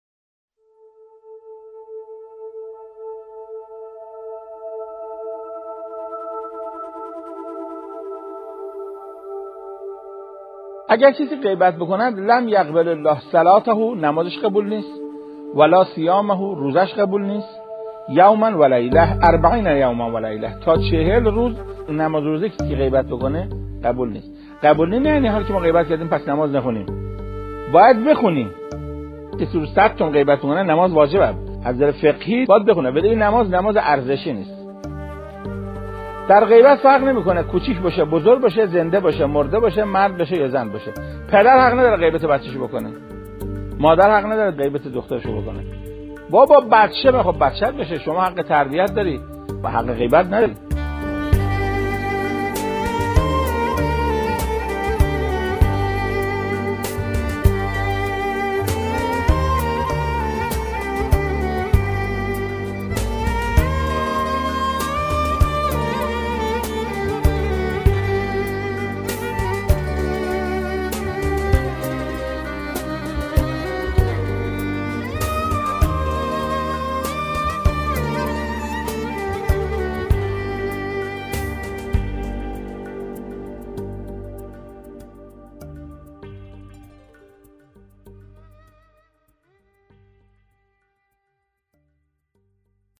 نواهنگ سخنان حجت الاسلام قرائتی